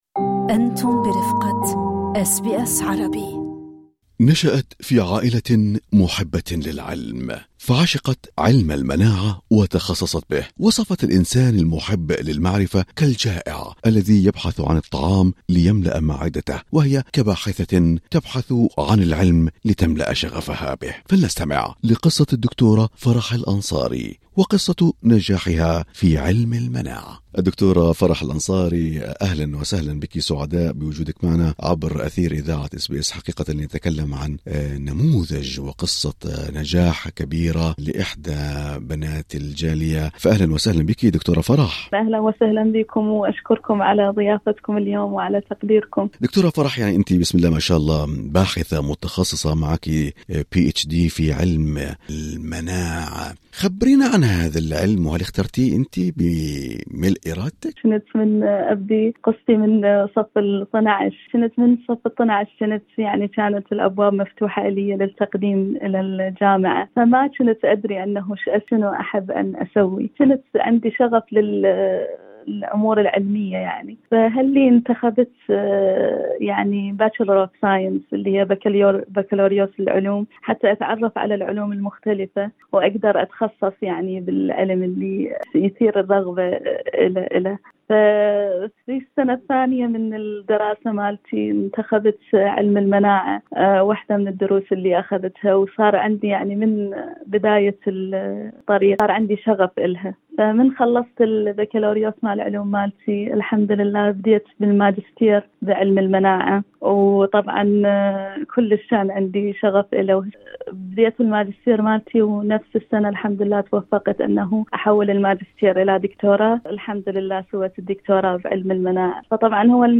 في حوارها مع إذاعة أس بي أس عربي